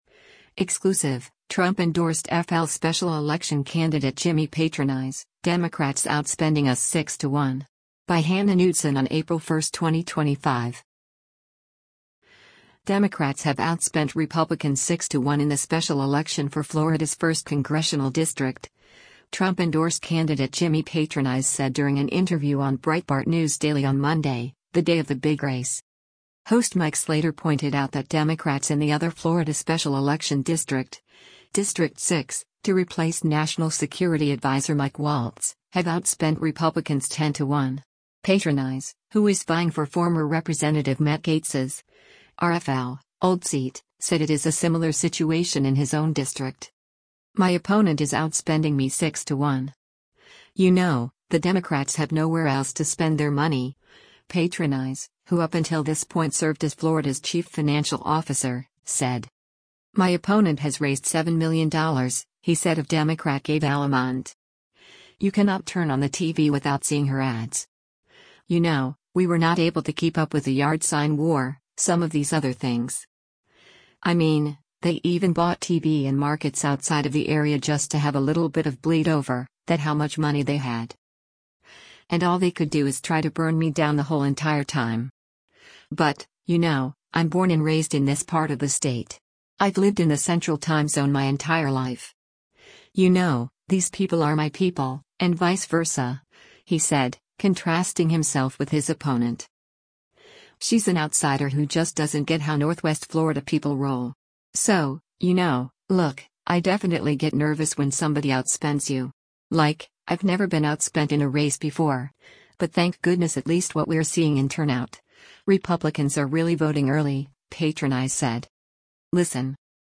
Democrats have outspent Republicans six to one in the special election for Florida’s 1st Congressional District, Trump-endorsed candidate Jimmy Patronis said during an interview on Breitbart News Daily on Monday, the day of the big race.
Breitbart News Daily airs on SiriusXM Patriot 125 from 6:00 a.m. to 9:00 a.m. Eastern.